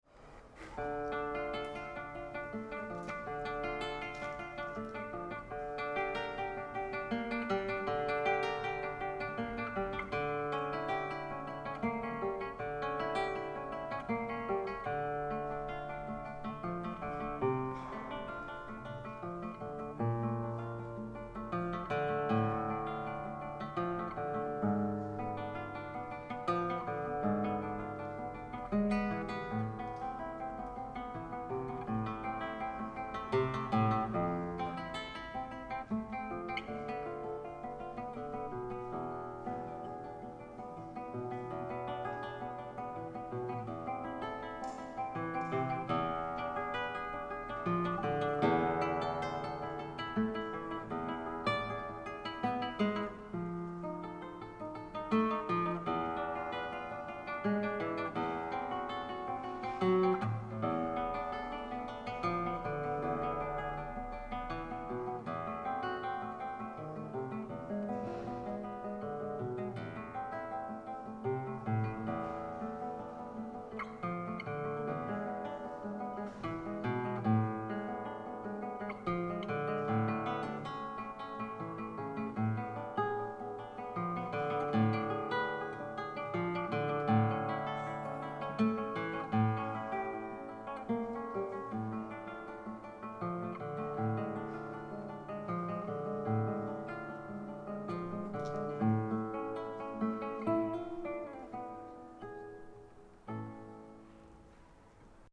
chitarra